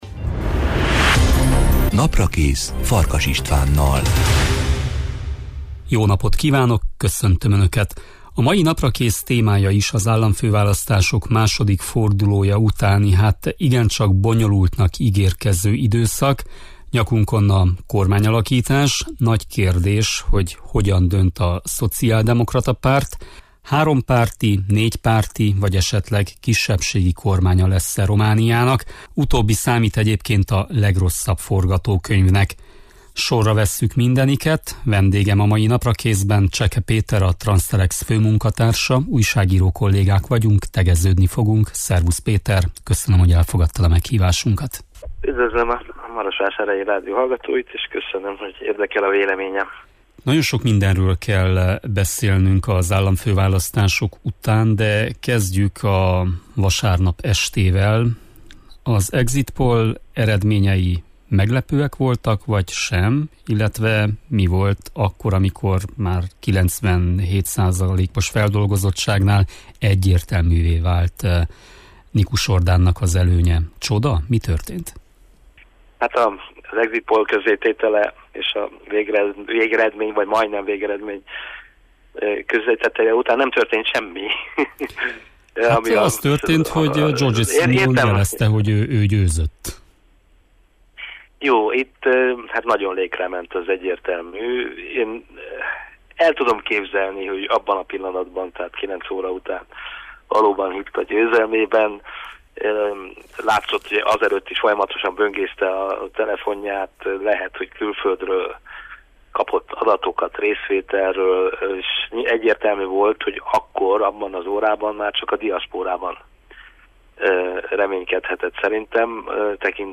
A soron következő Naprakészben erről beszélgetünk, értékeljük a választás második fordulójának eredményét, szó lesz Székelyföld szavazói aktivitásáról, valamint Orbán Viktor tihanyi beszédéről is.